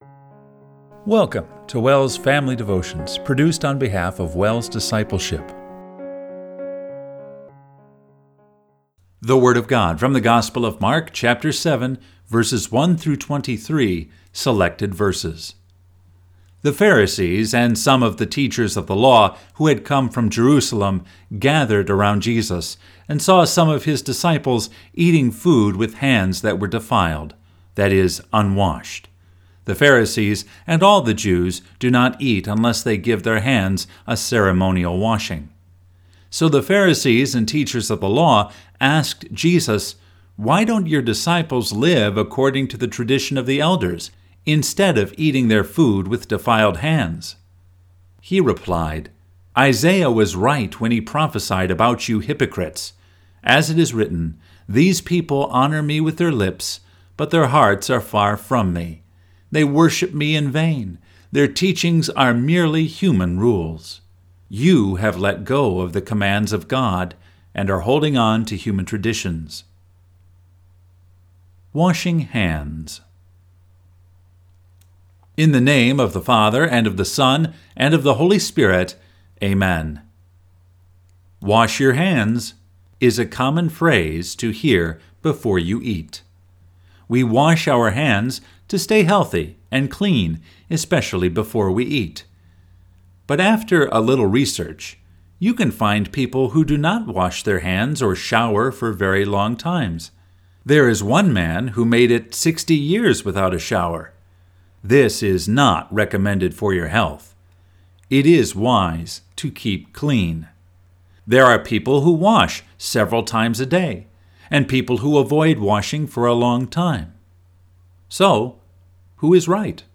Family Devotion – September 6, 2024